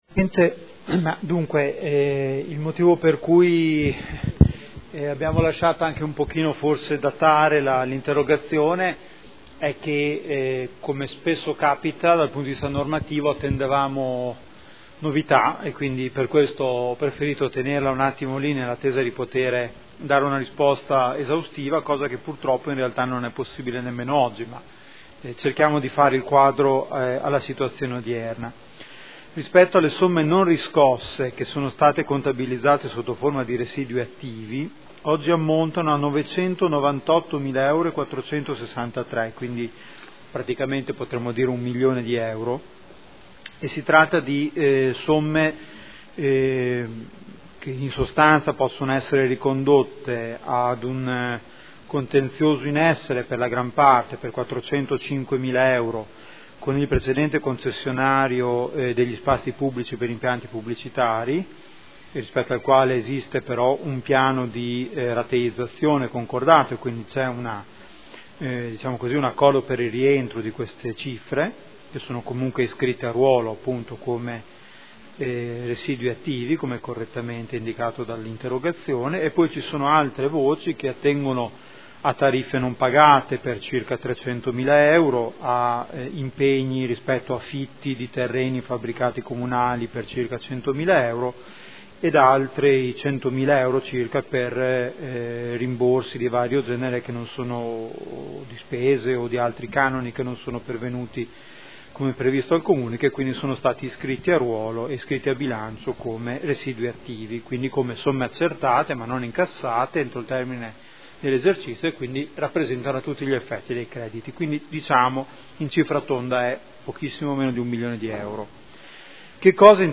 Giuseppe Boschini — Sito Audio Consiglio Comunale